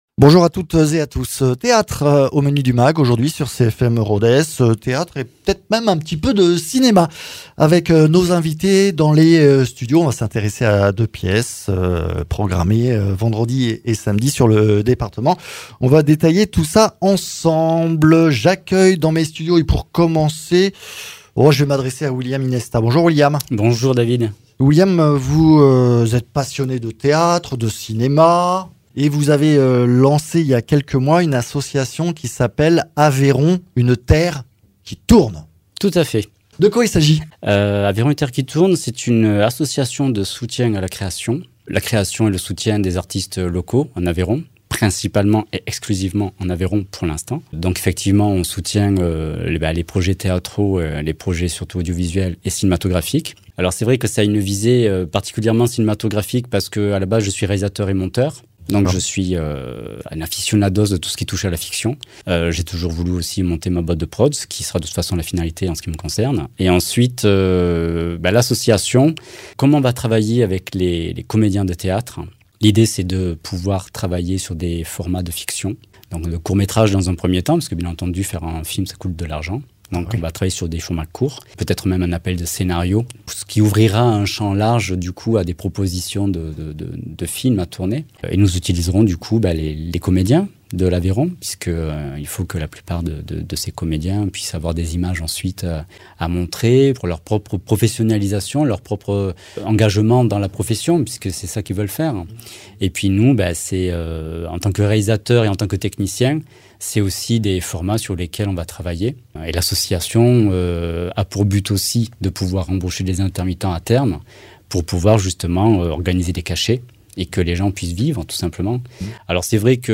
comédienne
metteur en scène